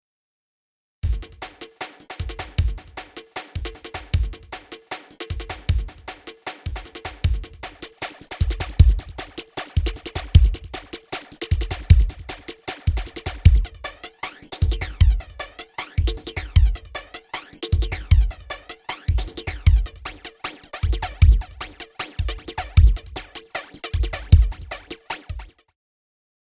フランジャーフランジャー
再生されている音と遅れた音の2つの音の干渉を利用して「シュワシュワシュワシュワ」という音を作り出すエフェクター。
flanger.mp3